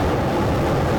Erekir turret SFX (of varying quality)
torch.ogg